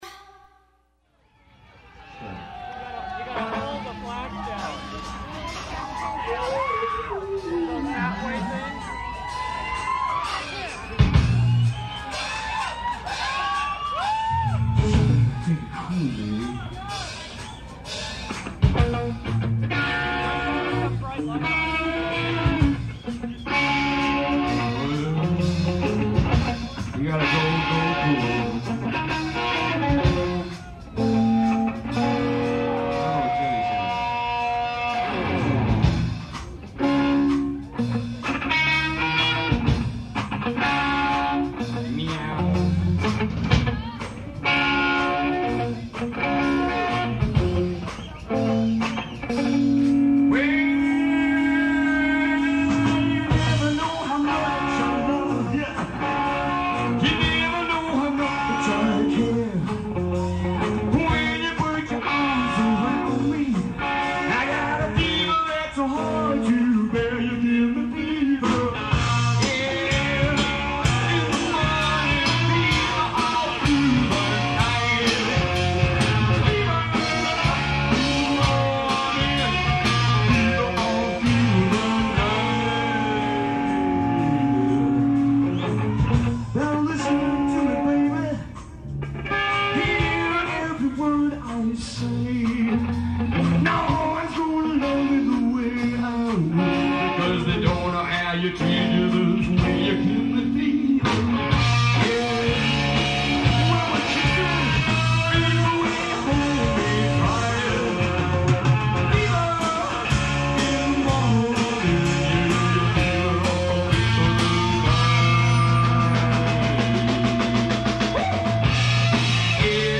Recorded live in Mulhouse, 1994
Vocals
Drums
Guitar
Bass